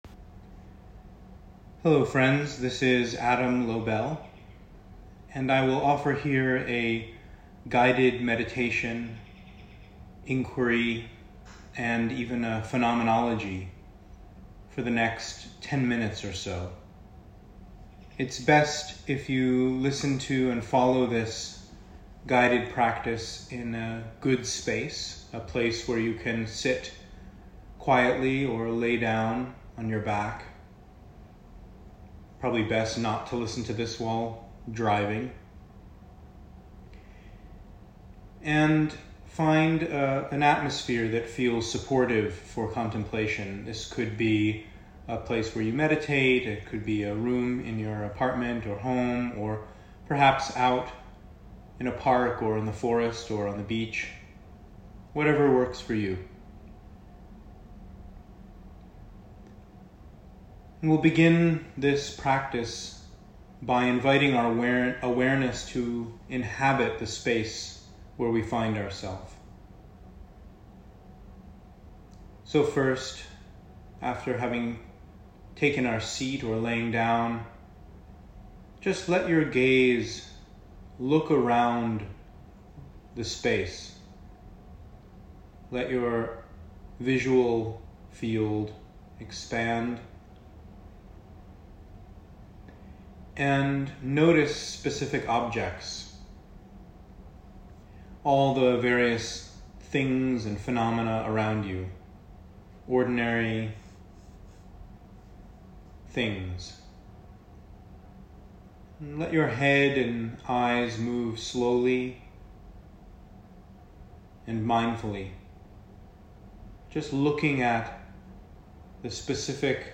Lessons-from-a-Solstice-Guided-Contemplative-Practice-on-Heat.m4a